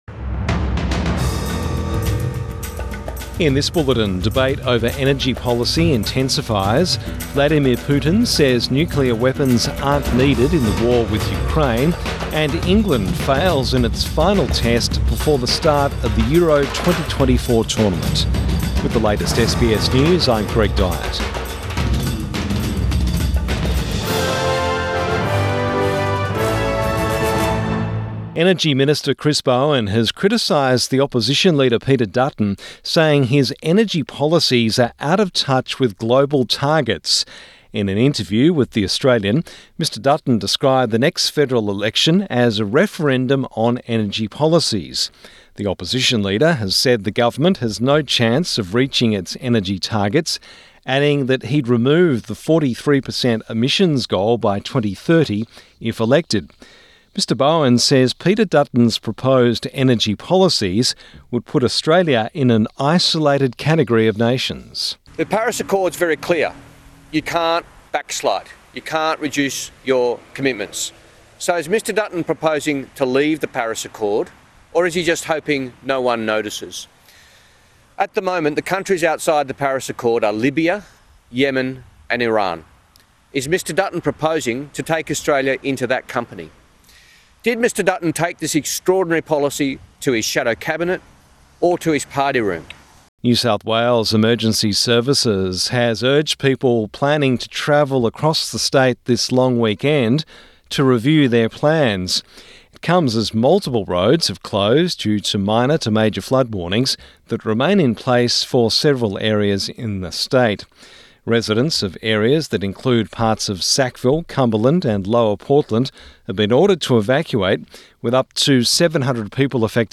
Evening News Bulletin 8 June 2024